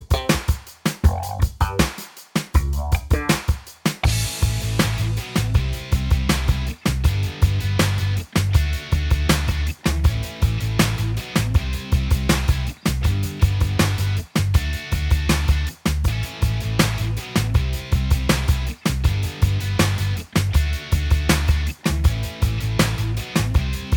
Minus All Guitars Rock 3:42 Buy £1.50